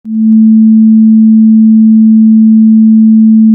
Low-frequency sound sometimes occurs in the Android emulator when recording audio with MediaRecorder
When testing it in the Android emulator, it sometimes produces a low-frequency sound throughout the entire file, but other times it works just fine.
The low-frequency sound: